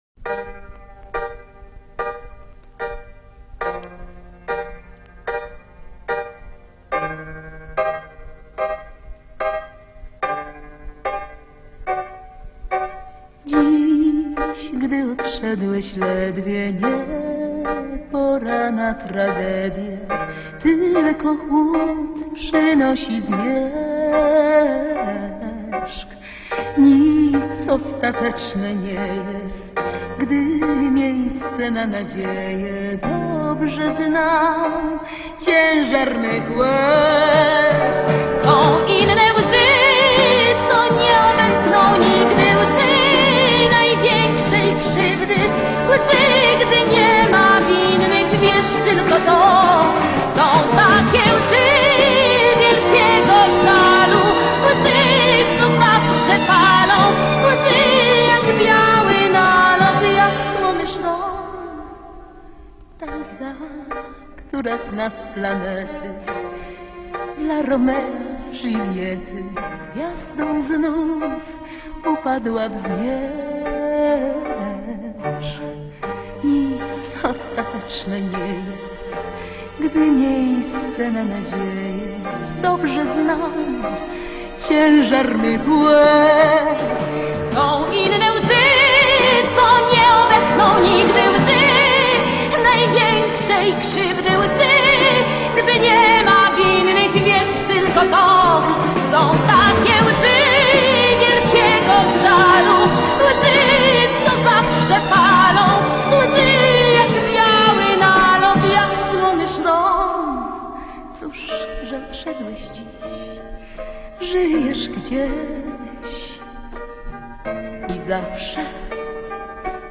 Polish female singer